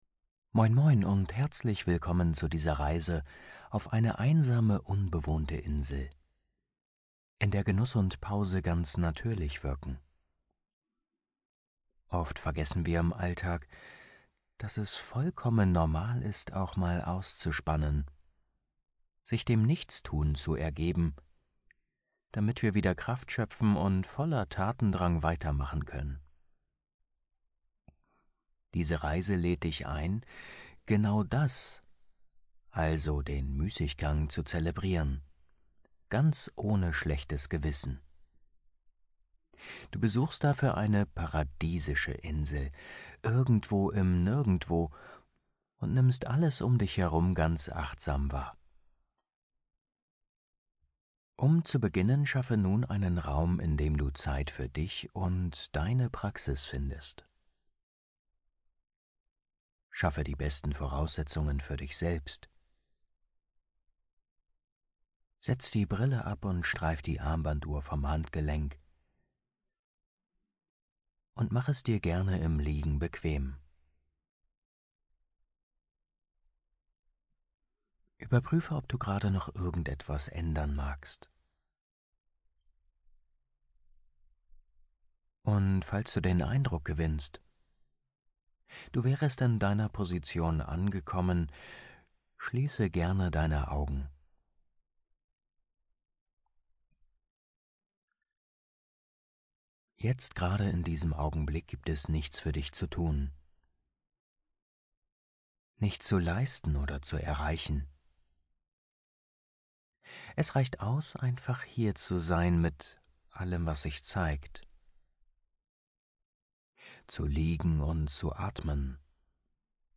Eine geführte Traumreise auf eine tropische Insel, die dich mitnimmt in Ruhe, Sinneswahrnehmung und Genuss. Eine Einladung, kleine Auszeiten und echte Erholung auch im Alltag wiederzufinden.